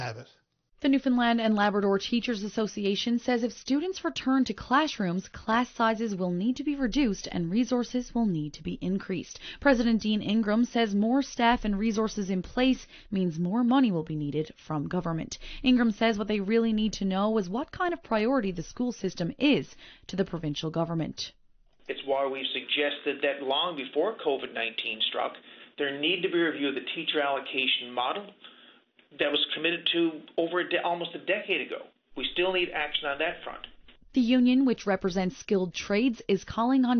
Media Interview - VOCM Evening News June 16, 2020